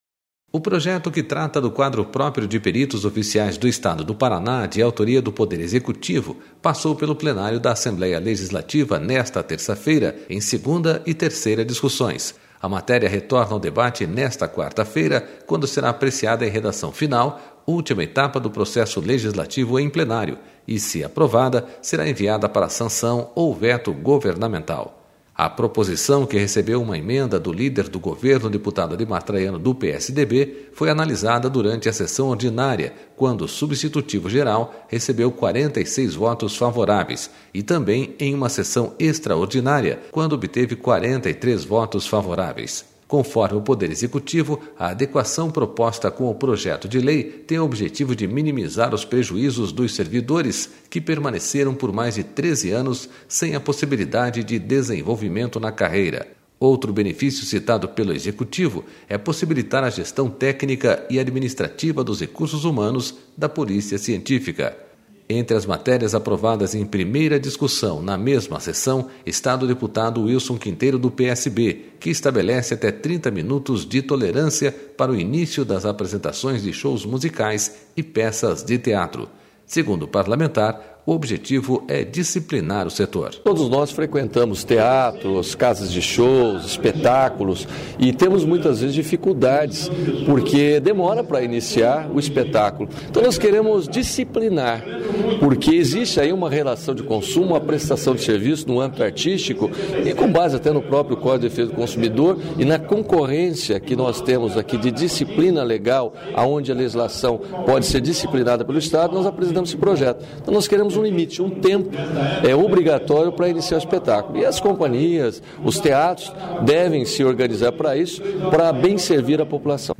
Notícia